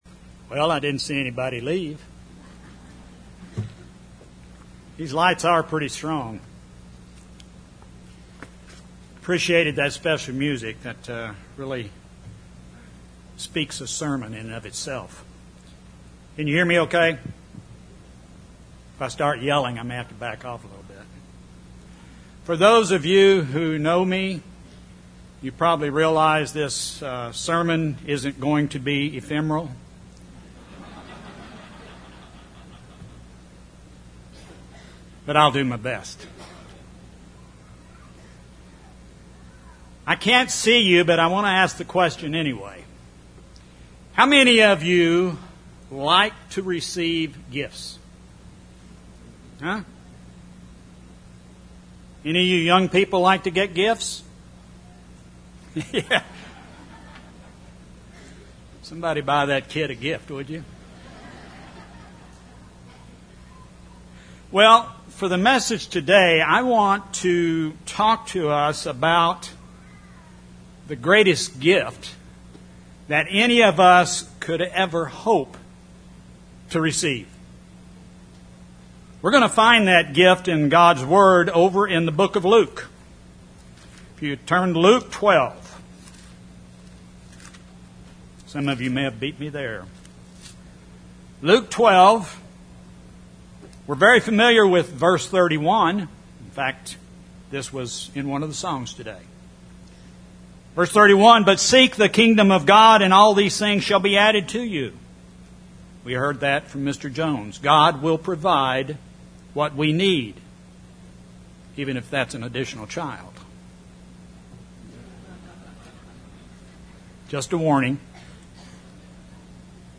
This sermon was given at the Branson, Missouri 2016 Feast site.